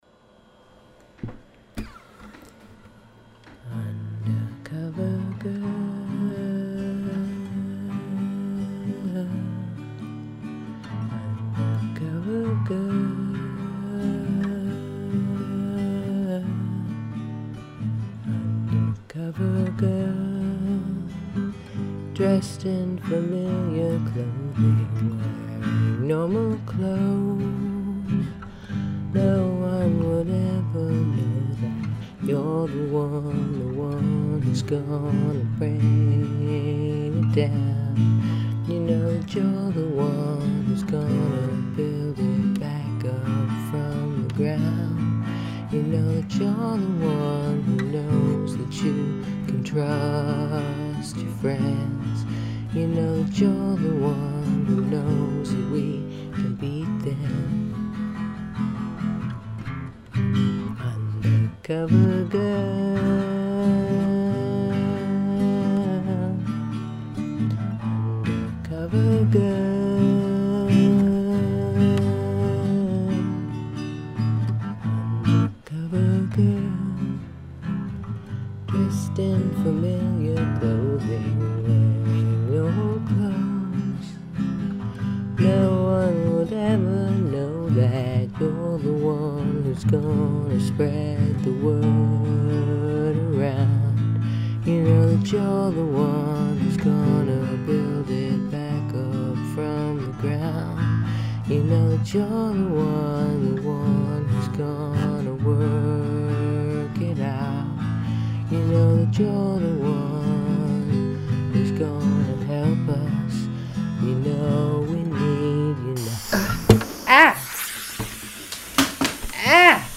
I've got my little heartfelt song going, when suddenly I am attacked by some kind of interference at the end.
WARNING -- gets loud and horrible at the end and I think I say a naughty word.